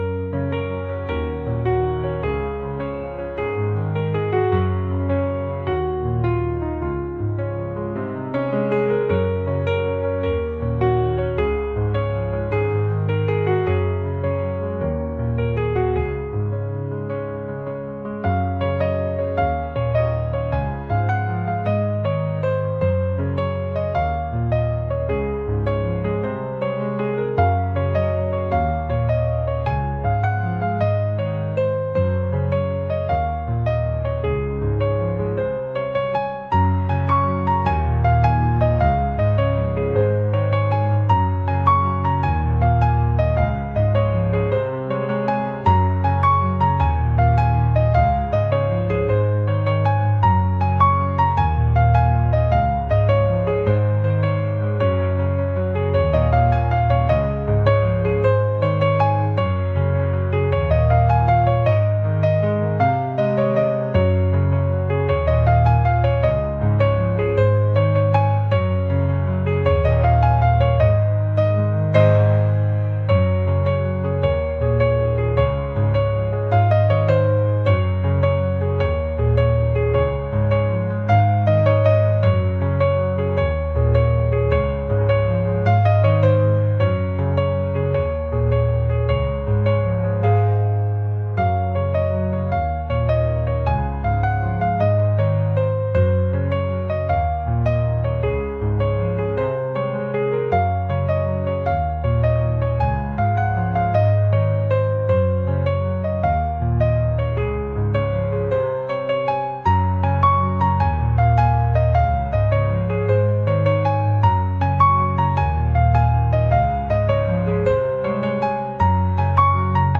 ambient | folk | cinematic